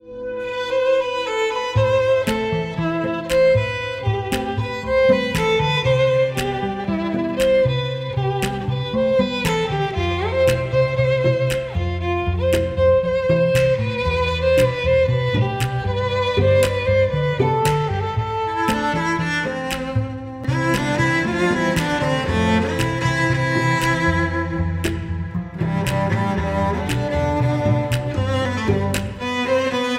(Không Lời) – Violin